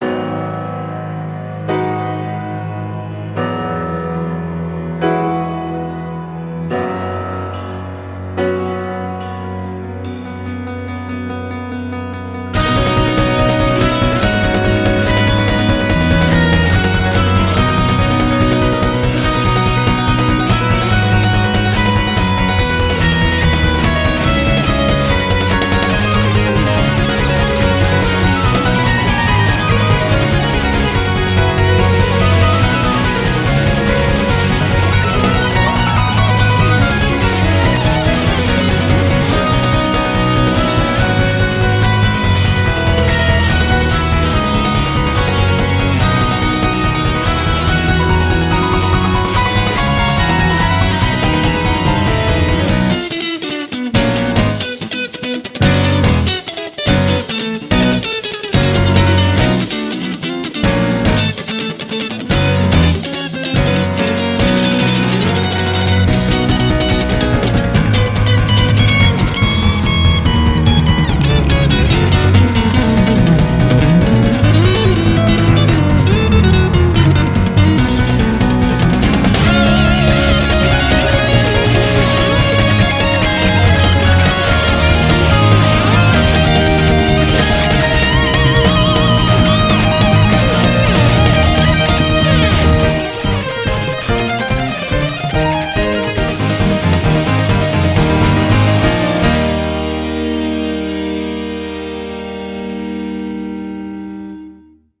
BPM140-287
Audio QualityMusic Cut